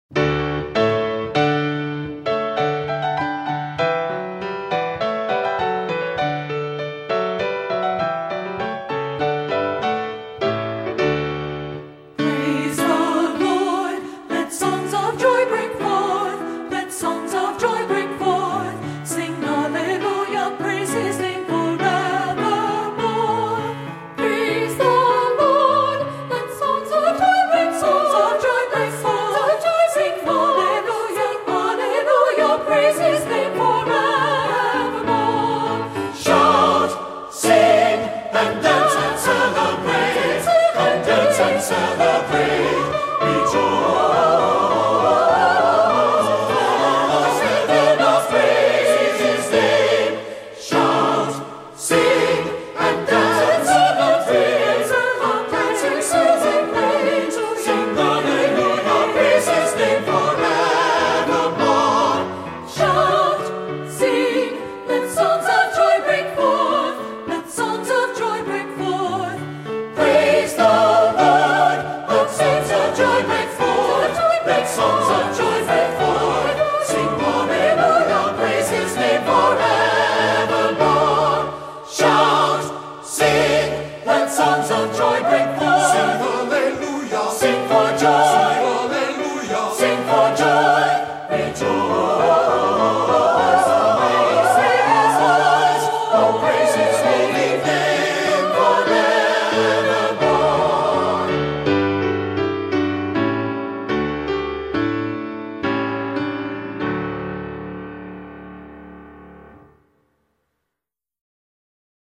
First Presbyterian Church
Anthem: "Praise The Lord", from Judas Maccabeus - George Frideric Handel (#793) Offertory: Dei Gloriam Handbell Choir